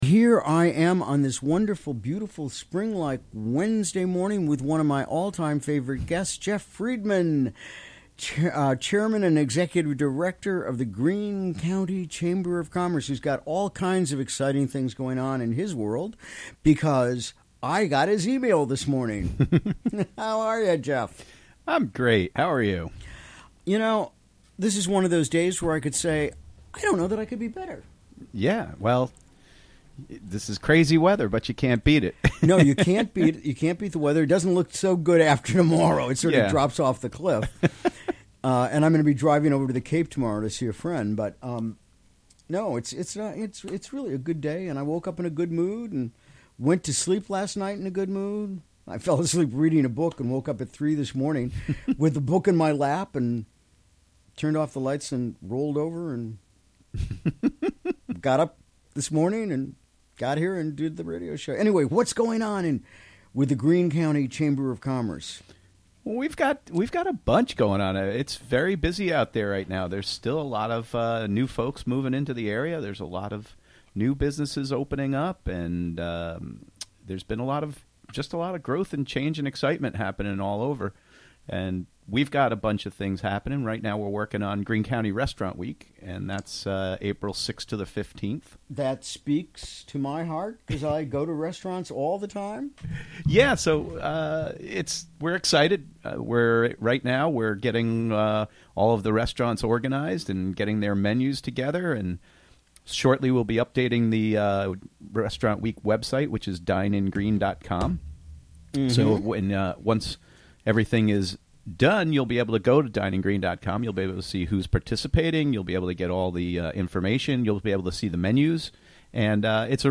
Recorded during the WGXC Morning Show on February 21, 2018.